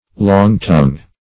Long-tongue \Long"-tongue`\, n.